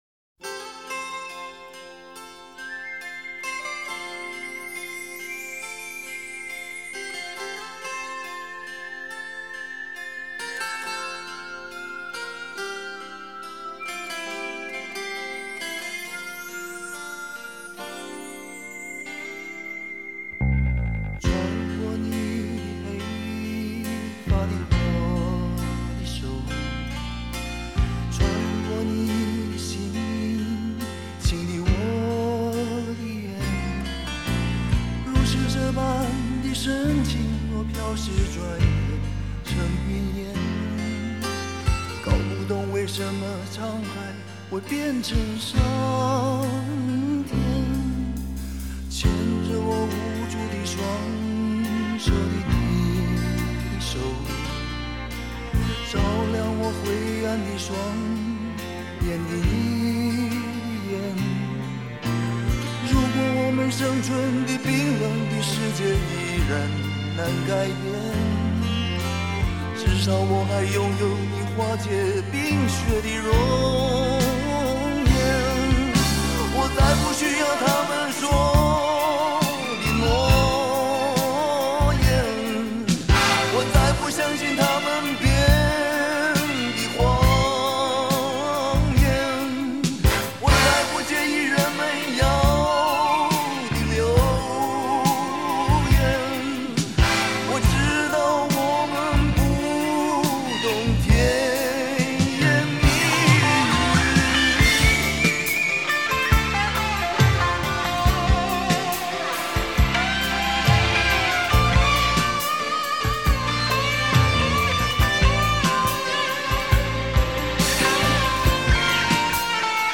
音乐类型：华语流行音乐